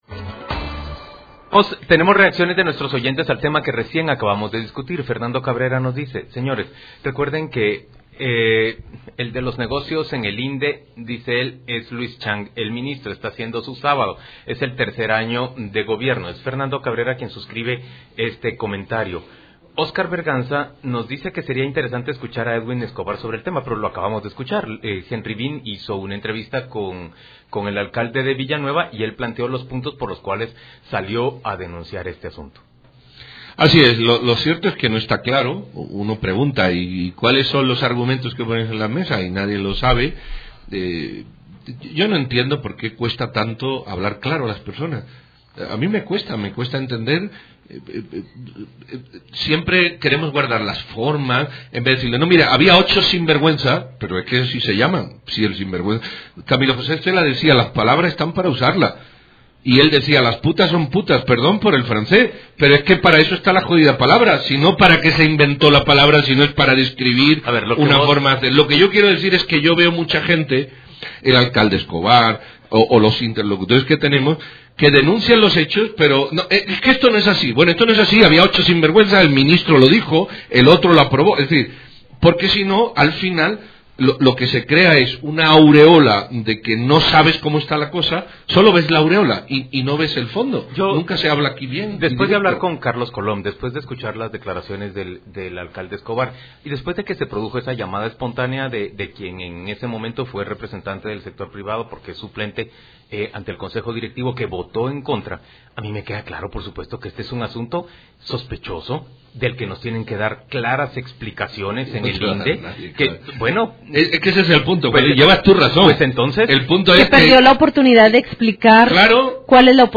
CON CRITERIO/RADIO INFINITA: Entrevista